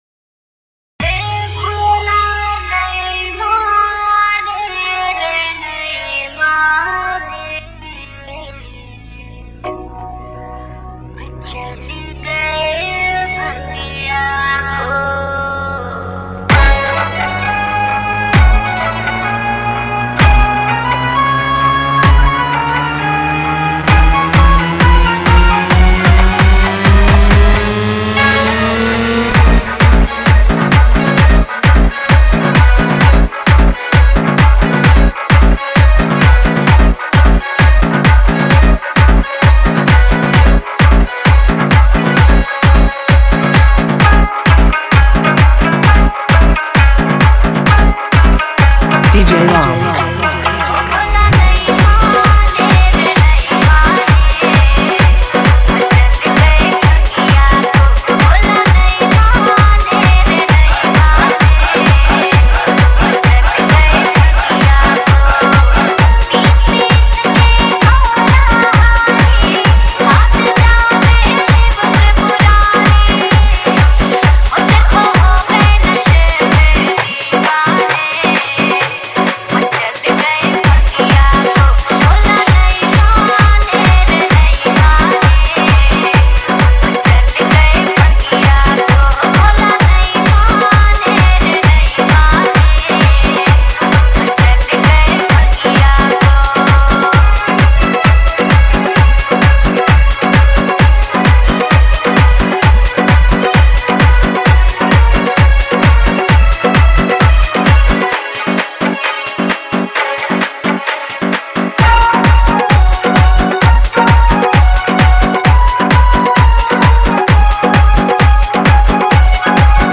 Shiv Shanker Dj Mixes Songs